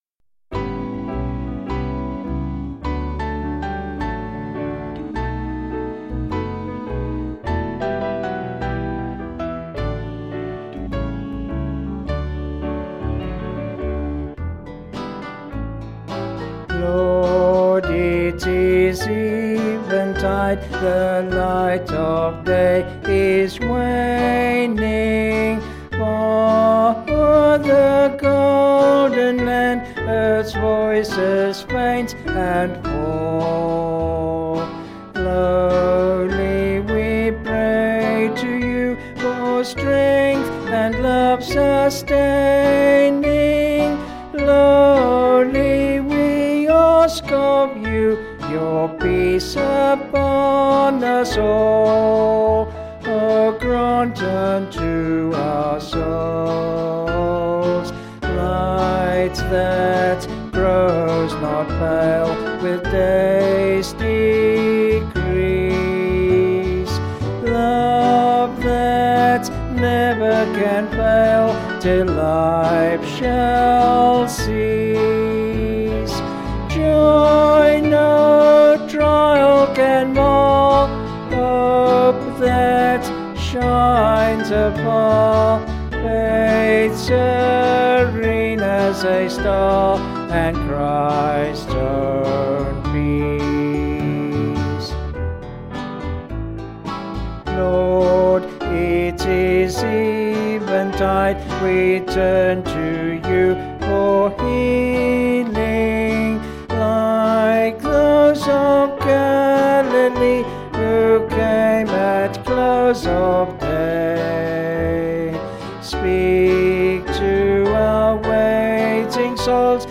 Vocals and Band   266.2kb Sung Lyrics